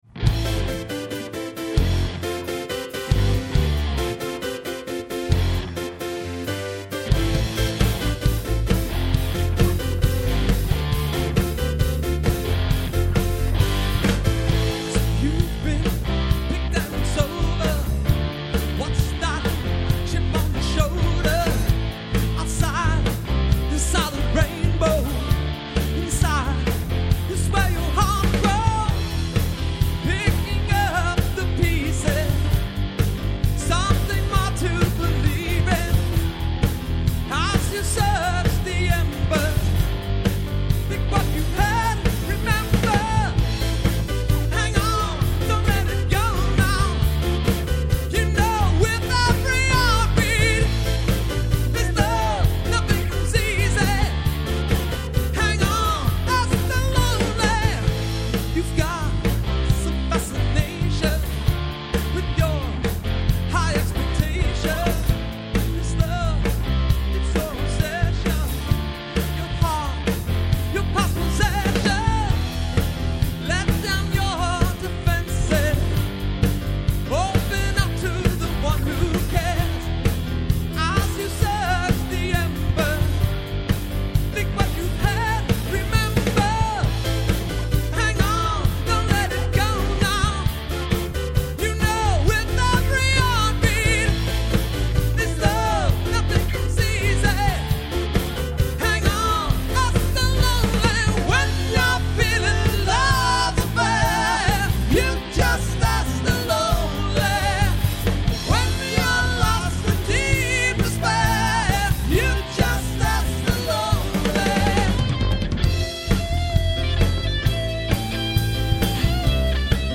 recorded directly off the mixing board